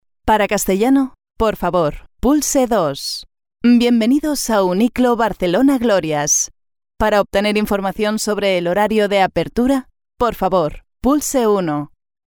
Warm, Zacht, Natuurlijk, Vriendelijk, Jong
Telefonie